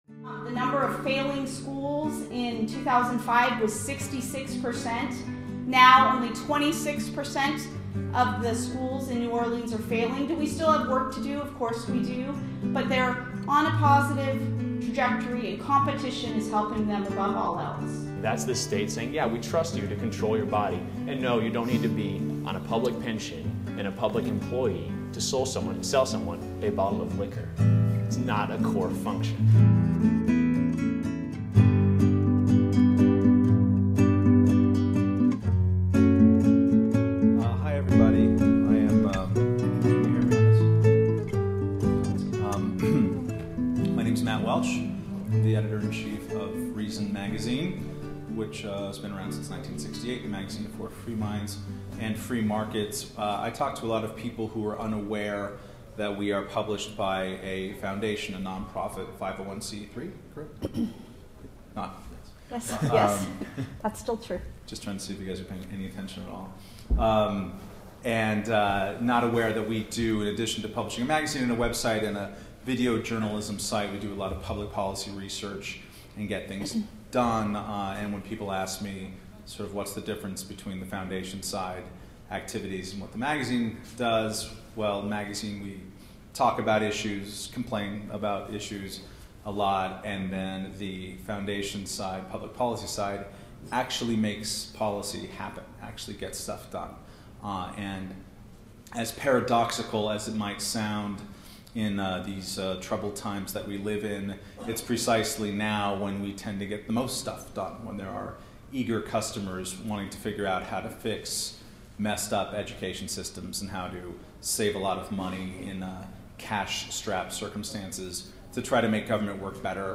Held each July in Las Vegas, FreedomFest is attended by around 2,000 libertarians and advocates of limited government.